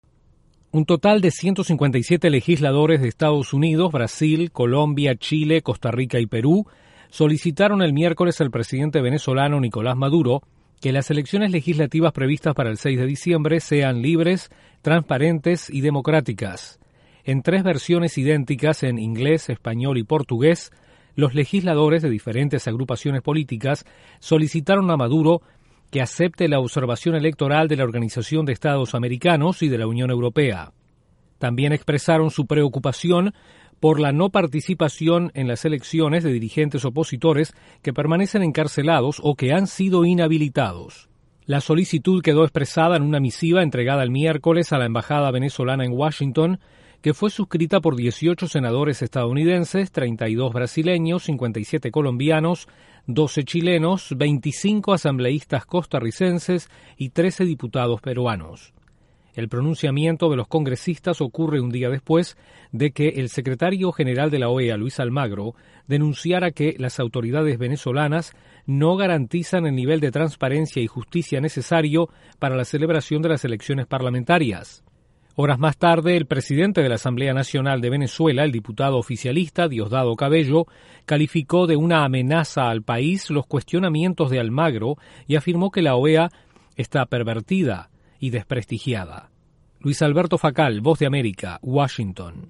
Legisladores de seis países, incluyendo EE.UU. piden elecciones libres en Venezuela. Desde la Voz de América en Washington informa